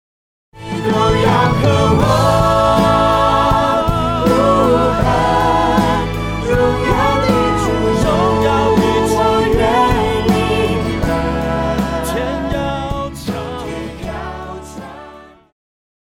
木吉他
樂團
教會音樂
演奏曲
獨奏與伴奏
有節拍器